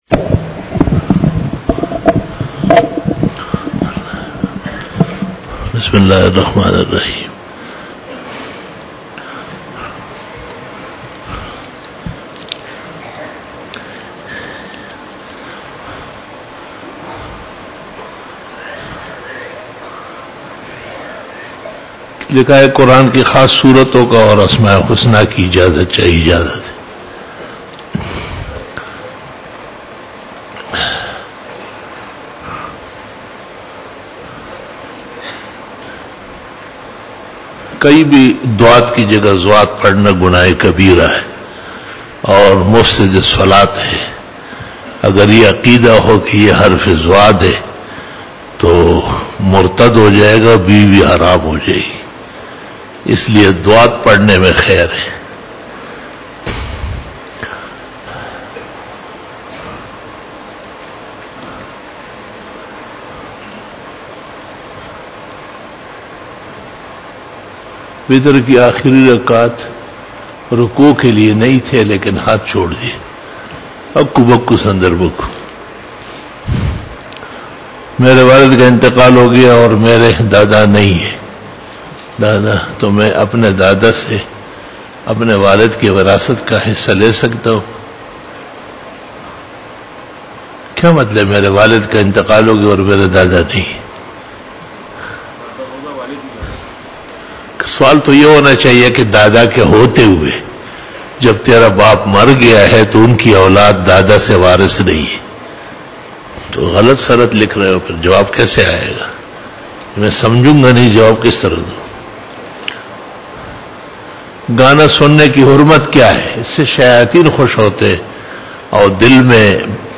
07 BAYAN E JUMA TUL MUBARAK (15 FEBRUARY 2019) (09 Jamadi Us Sani 1440H)
Khitab-e-Jummah 2019